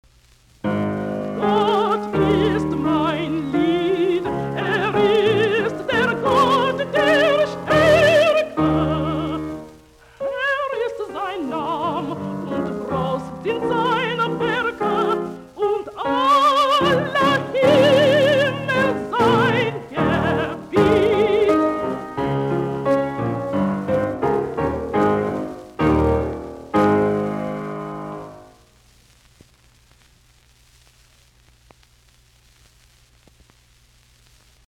Laulut, lauluääni, piano, op48